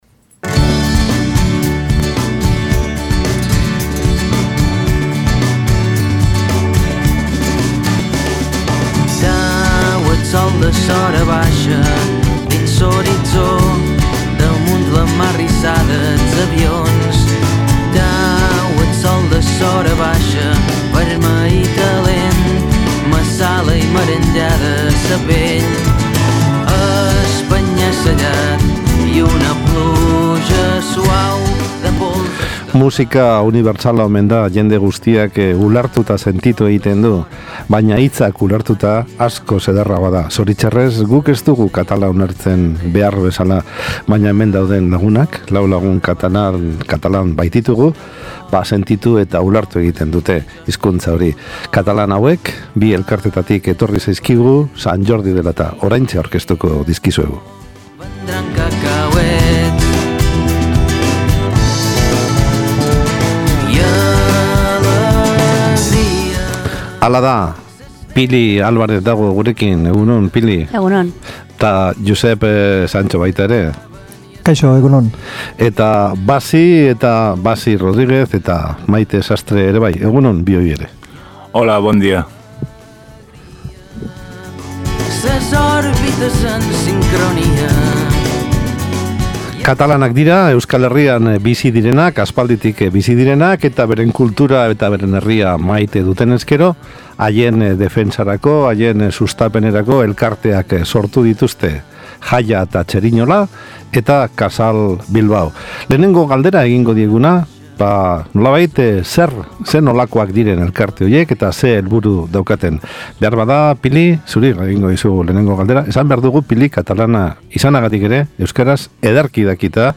Euren burua aurkeztu dute gure mikrofonoetan, eta biharko egitarauari buruzko xehetasunak eman dizkigute.